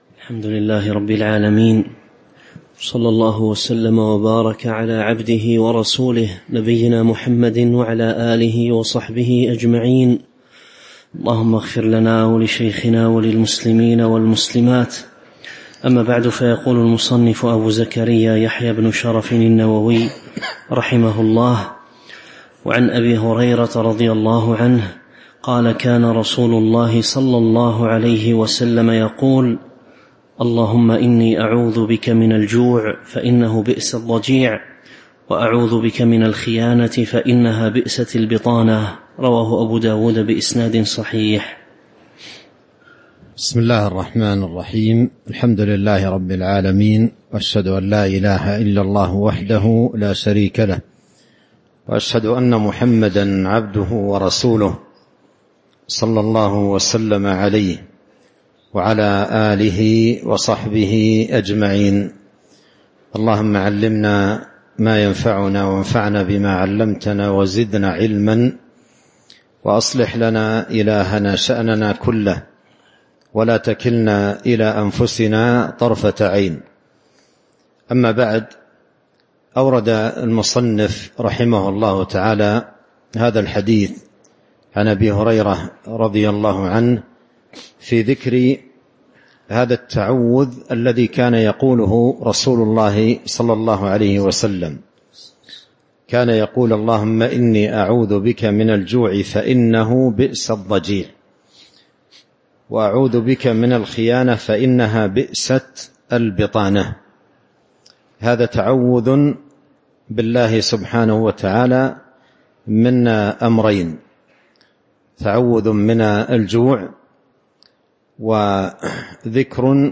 تاريخ النشر ٧ رمضان ١٤٤٥ هـ المكان: المسجد النبوي الشيخ: فضيلة الشيخ عبد الرزاق بن عبد المحسن البدر فضيلة الشيخ عبد الرزاق بن عبد المحسن البدر باب فضل الدعاء (07)